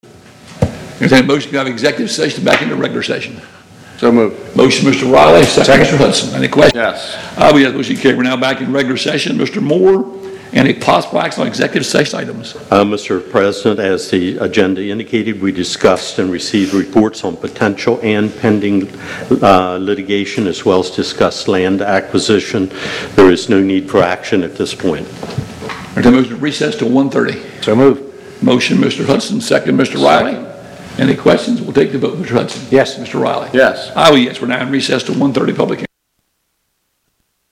County Council Meeting (Agenda amended on 6/20/19) | Sussex County